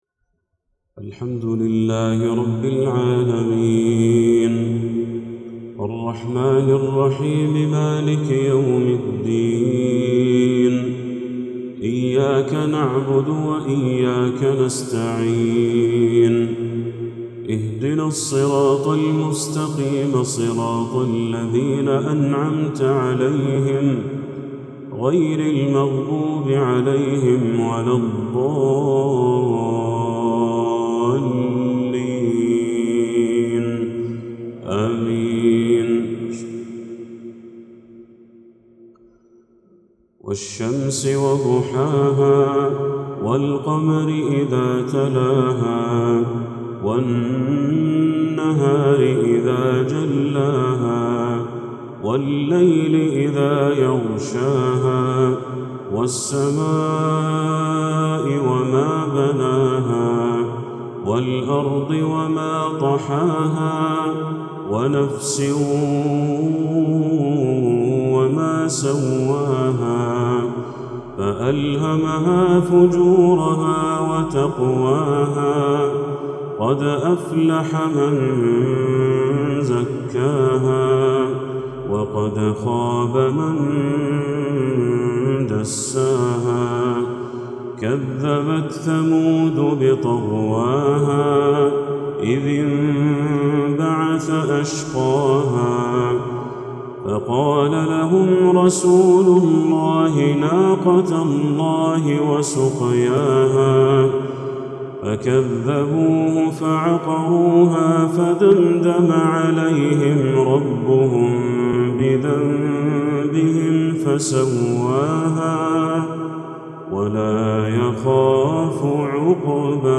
تلاوات الفروض
صلاة المغرب من جامع إمام الدعوة بمكة المكرمة